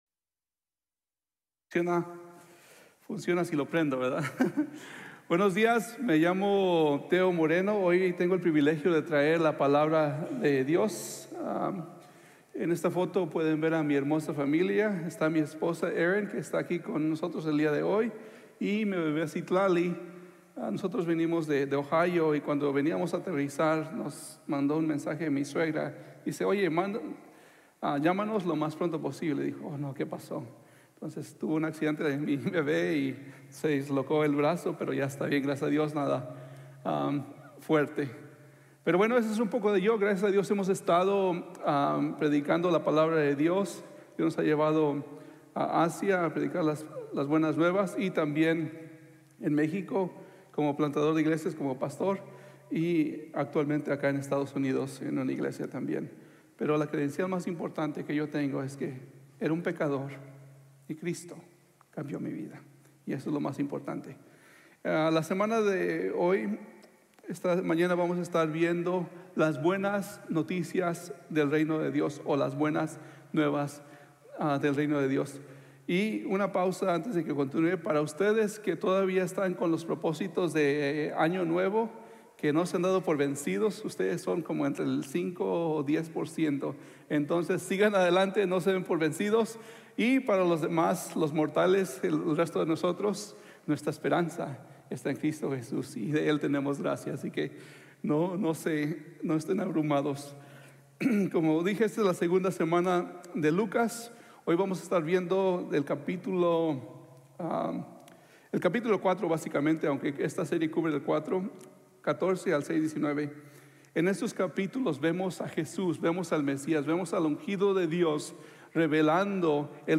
La Buena Nueva del Reino de Dios | Sermon | Grace Bible Church